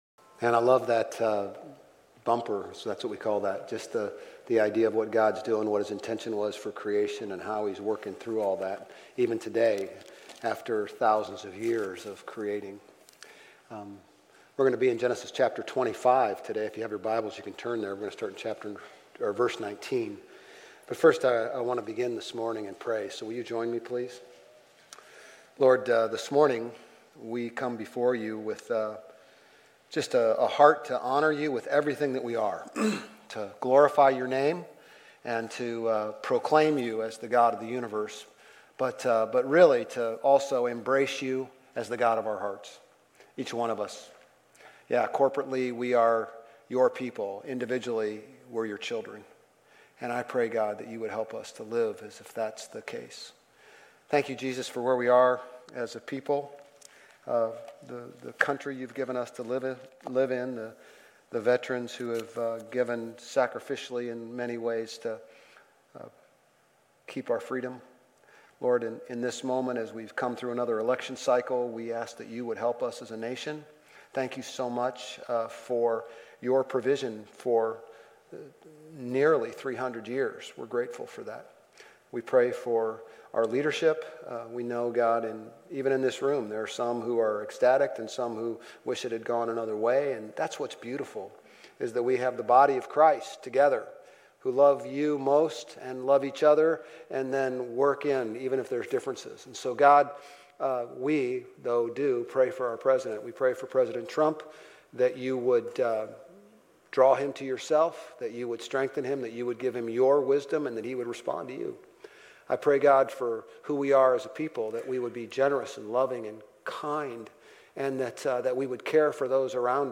Grace Community Church Old Jacksonville Campus Sermons Gen 25:29-34, Gen 27:1-41 - Jacob and Essau Birthright and Blessing Nov 10 2024 | 00:35:52 1x 00:00 / 00:35:52 Subscribe Share RSS Feed Share Link Embed